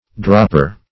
dropper \drop"per\, n.